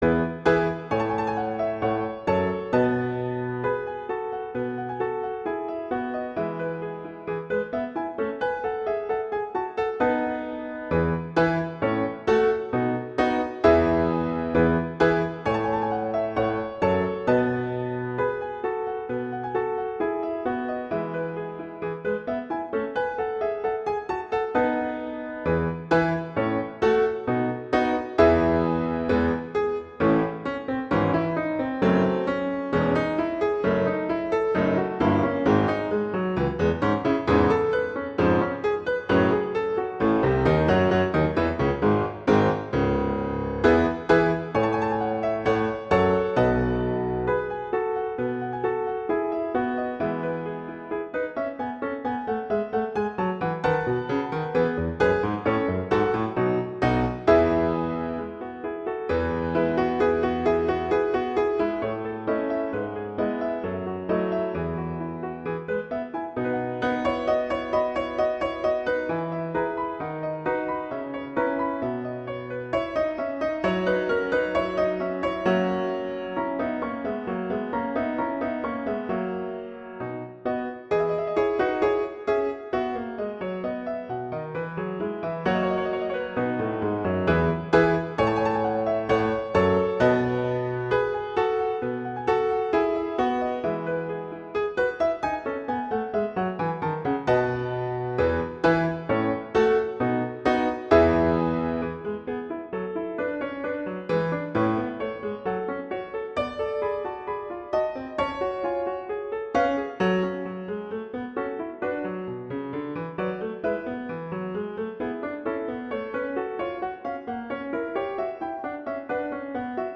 classical, children
E major
♩=132 BPM